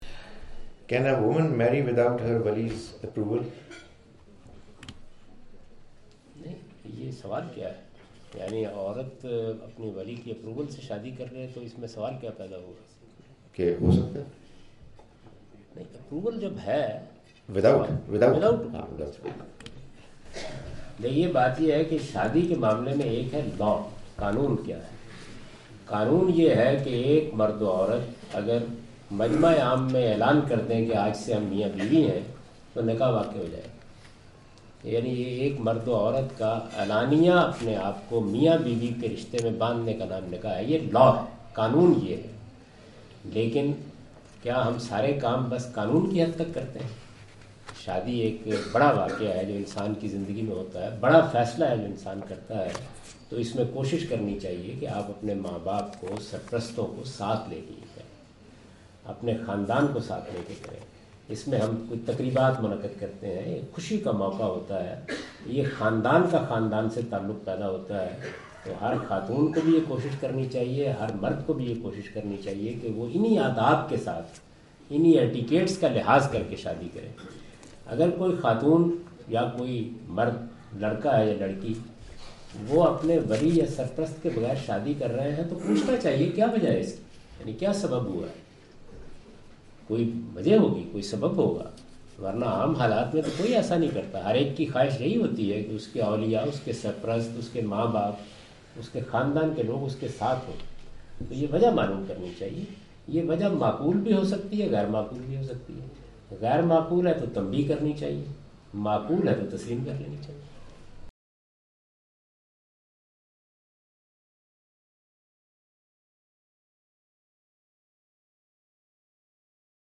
Category: English Subtitled / Questions_Answers /
Javed Ahmad Ghamidi answer the question about "marrying without guardian's approval" during his visit to Manchester UK in March 06, 2016.
جاوید احمد صاحب غامدی اپنے دورہ برطانیہ 2016 کے دوران مانچسٹر میں "ولی کی اجازت کے بغیر شادی" سے متعلق ایک سوال کا جواب دے رہے ہیں۔